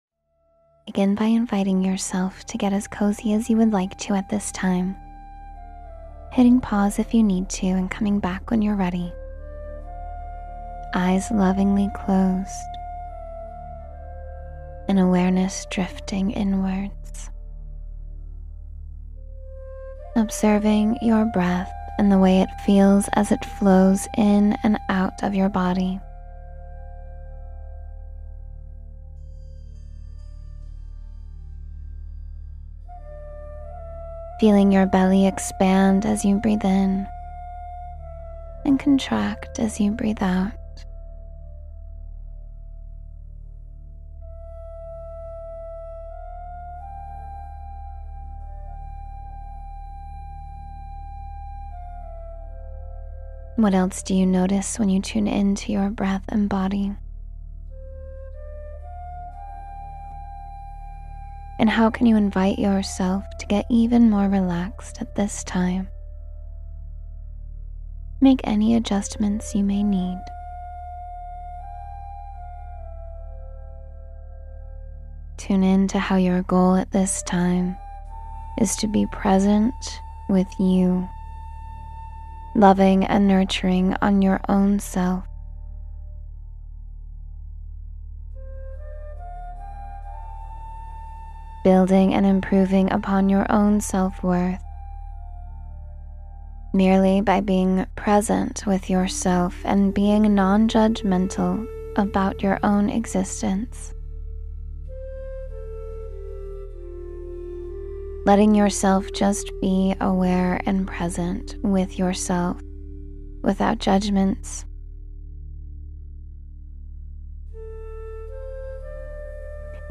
Strengthen Your Sense of Self and Purpose — Meditation for Confidence